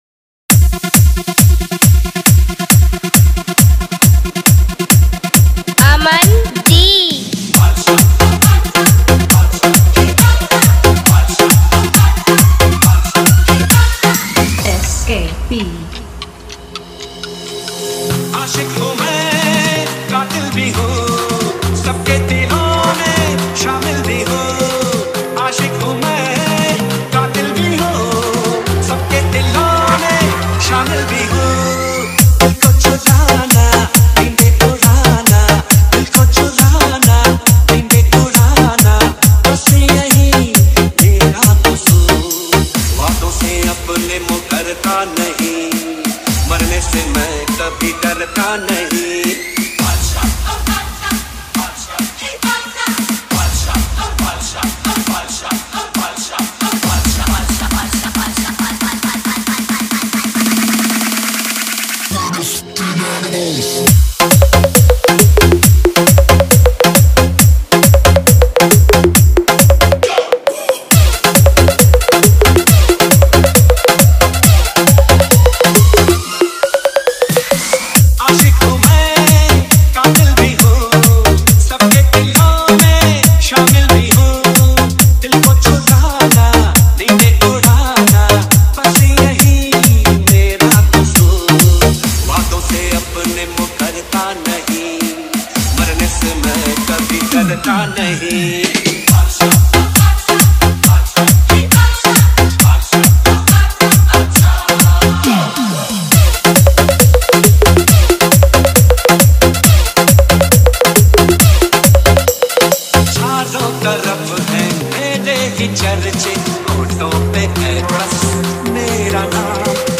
Hindi Dj Songs